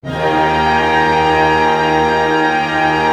Index of /90_sSampleCDs/Roland - String Master Series/ORC_ChordCluster/ORC_Pentatonic